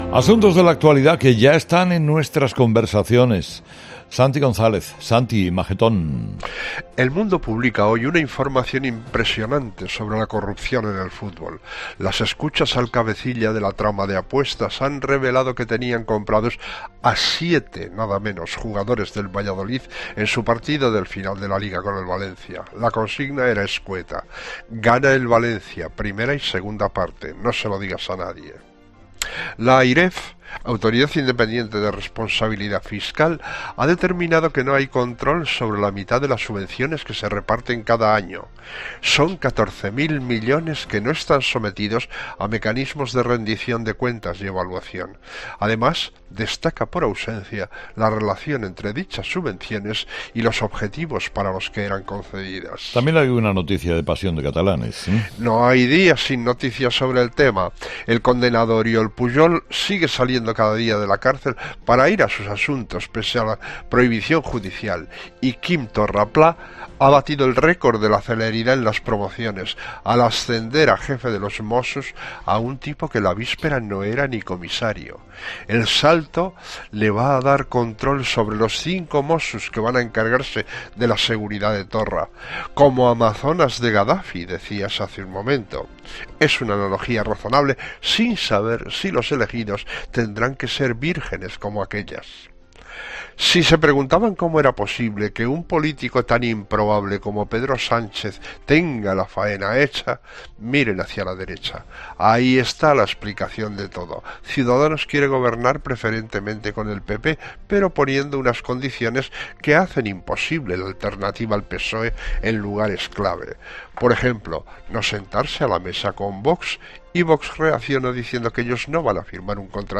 El análisis de la actualidad de Santi González en 'Herera en COPE'.